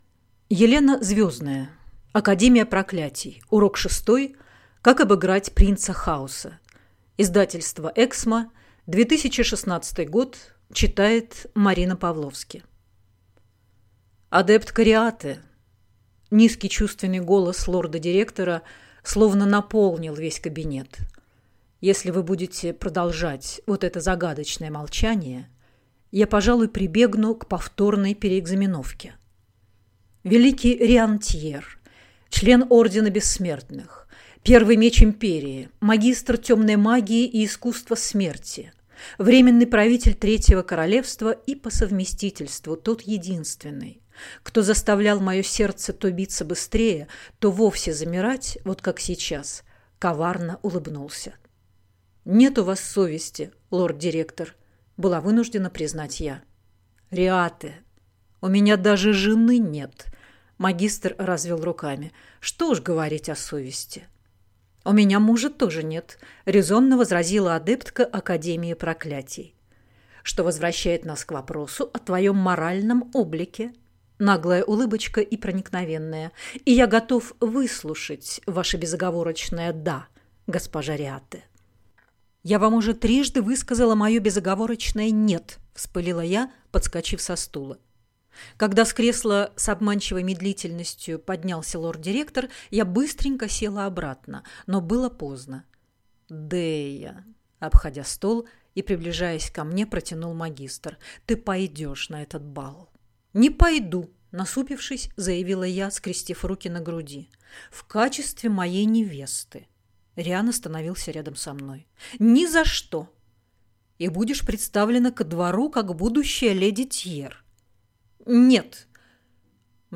Аудиокнига Урок шестой: Как обыграть принца Хаоса - купить, скачать и слушать онлайн | КнигоПоиск